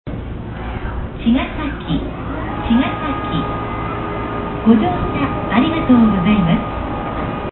駅名連呼「茅ヶ崎駅」 茅ヶ崎駅の駅名連呼です。
以前は、乗り換え放送がありましたが現在ではカットされています。